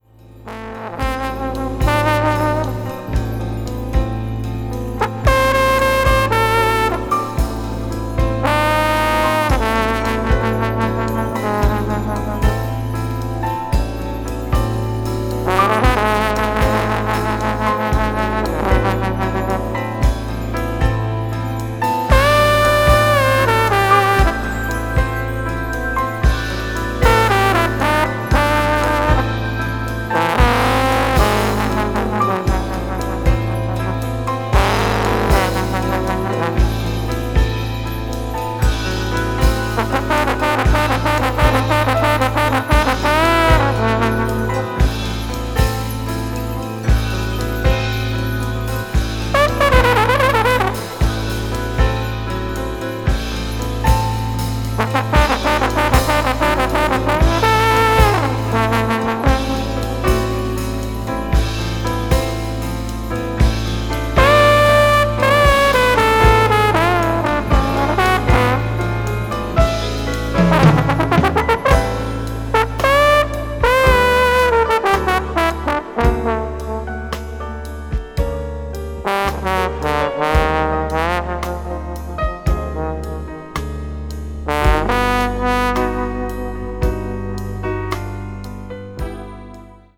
media : EX/EX(一部わずかにチリノイズが入る箇所あり)